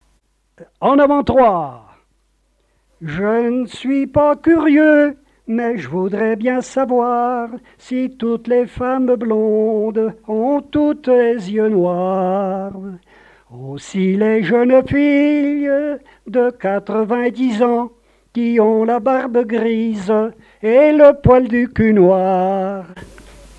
Quadrille - En avant trois
Genre strophique
répertoire de chansons et airs à l'accordéon
Pièce musicale inédite